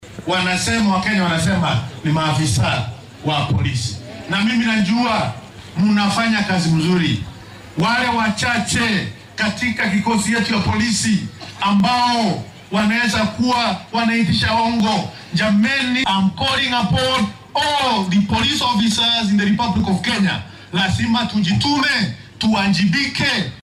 Wasiirka wasaaradda adeegga dadweynaha , horumarinta karaanka aadanaha iyo barnaamijyada gaarka ah ee dalka Geoffrey Ruku ayaa sheegay in dowladdu aynan marnaba u dulqaadan doonin oo ay tallaabo adag ka qaadeysa saraakiisha dowladda ee shacabka ka dalbado bixinta laaluushka si ay ugu adeegaan. Xilli uu ku sugnaa deegaanka Galbeedka Kibwezi ee ismaamulka Makueni , ayuu Ruku hoosta ka xarriiqay in warbixinnada guddiga anshaxa iyo la dagaallanka musuqmaasuqa ee EACC ay muujiyaan in musuqa uu ku badan yahay waaxda garsoorka iyo adeegga qaran ee booliiska dalka.